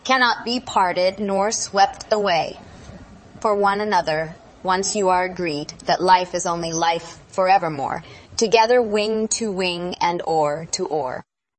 tortoise-tts - (QoL improvements for) a multi-voice TTS system trained with an emphasis on quality